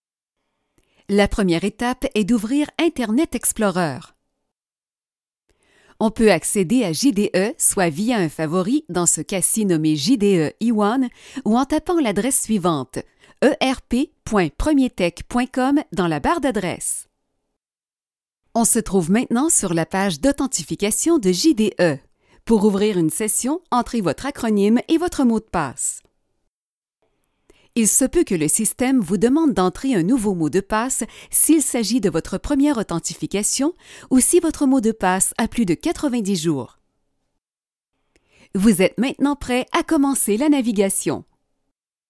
Narration
Capsule de formation
Avec une diction claire et précise, nos voix s’adapteront avec l’émotion et la tonalité que vous désirez!